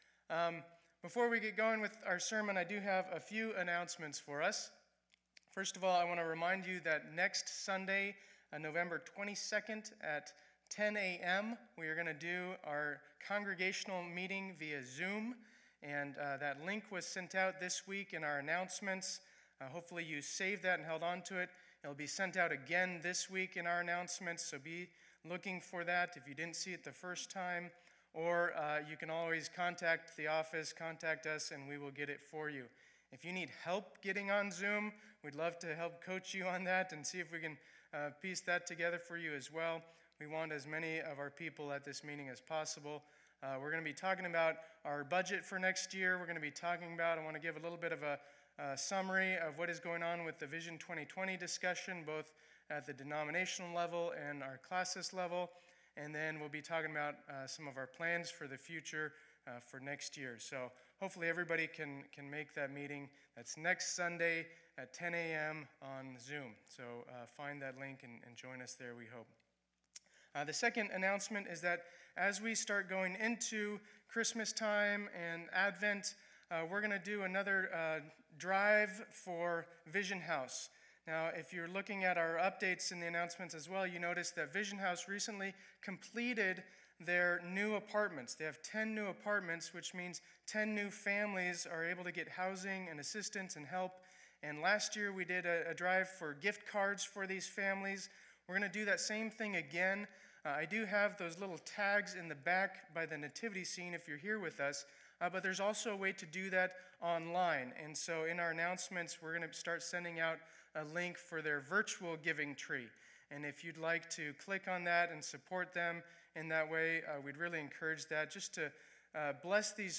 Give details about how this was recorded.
2020-11-15 Sunday Service